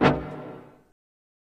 brass hit 1.0.wav